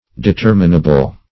Determinable \De*ter"mi*na*ble\, a. [L. determinabilis finite.